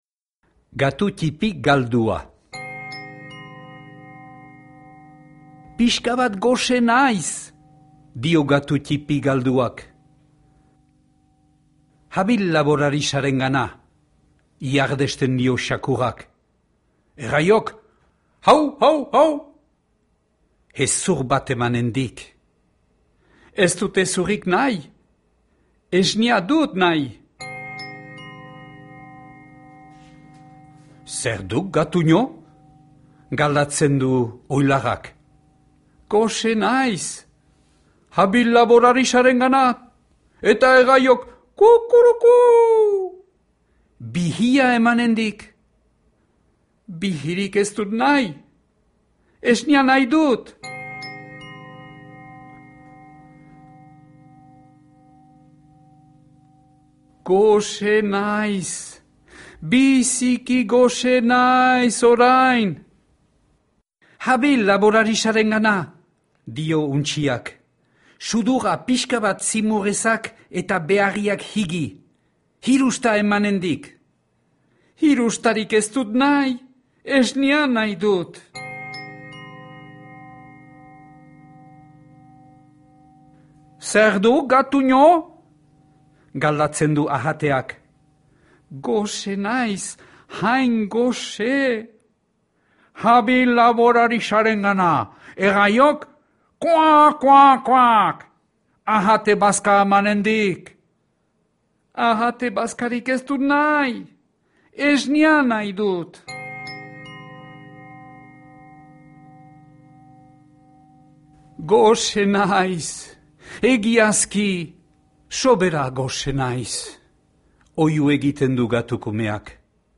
Gatu ttipi galdua - Batuaz - ipuina entzungai
Flammarion Père Castor saileko Petit chat perdu albumaren itzulpena, CD batean grabatua.